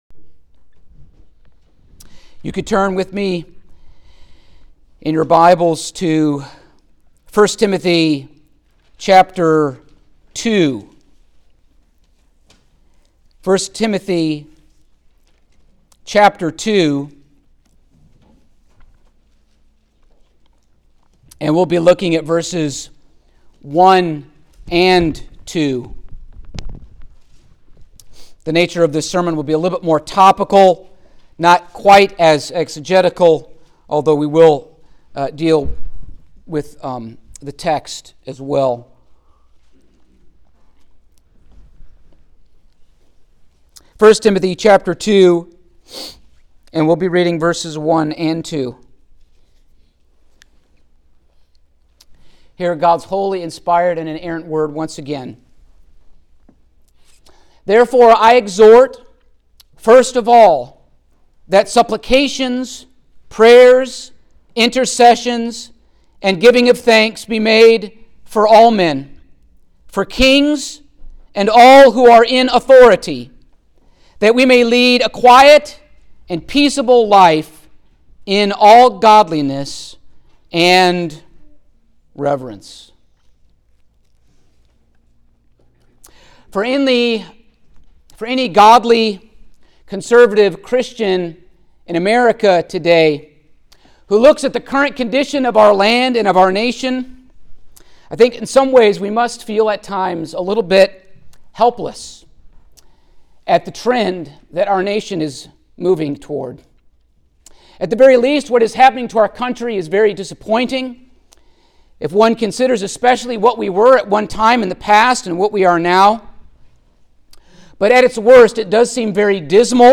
Election Day Sermons
Service Type: Sunday Morning